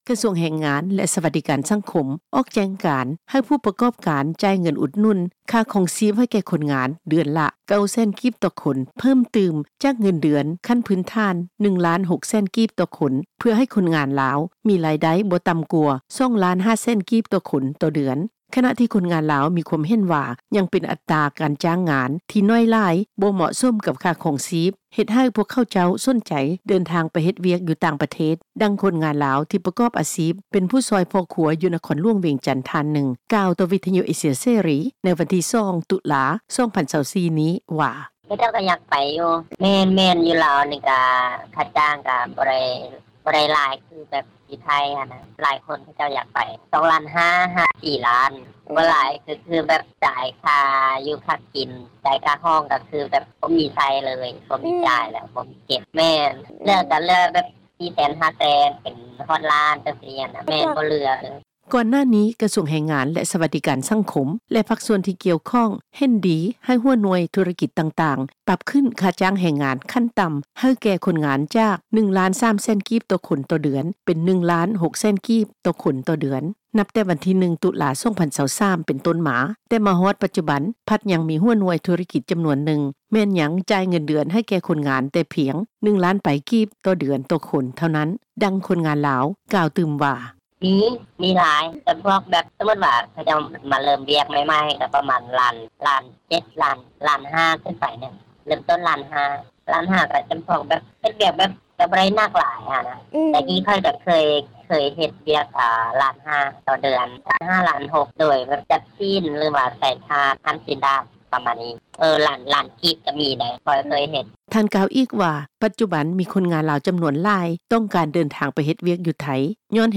ດັ່ງ ພະນັກງານ ບໍລິສັດຈັດຫາງານ ຢູ່ນະຄອນຫລວງວຽງຈັນ ກ່າວຕໍ່ວິທຍຸ ເອເຊັຽເສຣີ ໃນມື້ດຽວກັນນີ້ວ່າ:
ດັ່ງ ເຈົ້າໜ້າທີ່ ທີ່ເຮັດວຽກ ກ່ຽວກັບແຮງງານ ຢູ່ແຂວງທາງພາກກາງຂອງປະເທດ ທ່ານນຶ່ງກ່າວວ່າ: